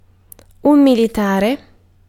Ääntäminen
US : IPA : /ˈmɪl.ɪ.tɛɹ.i/ UK : IPA : /ˈmɪl.ɪ.tɹɪ/